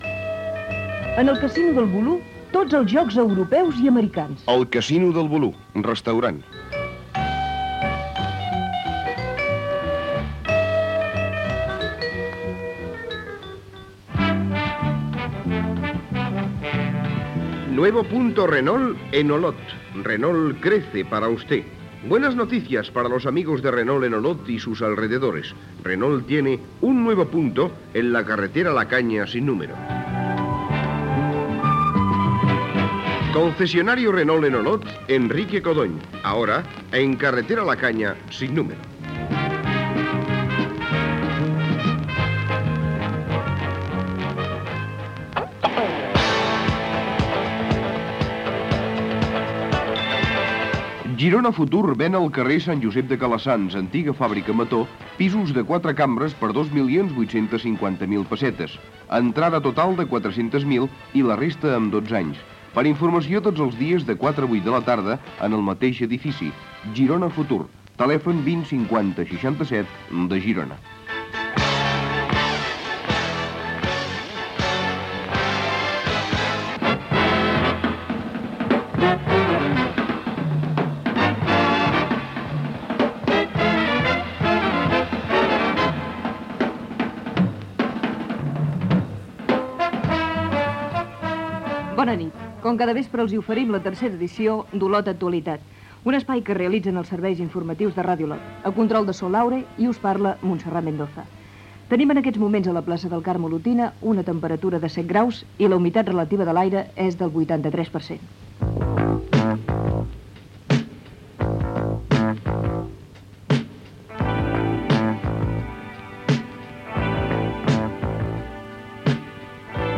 Publicitat, presentació del programa, temperatura, sumari informatiu, eleccions al Parlament de Catalunya
Informatiu
FM